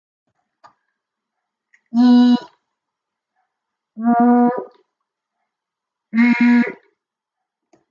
Uitspraak van medeklinkers:
y w r (audio)
7-y-w-r-_audio.mp3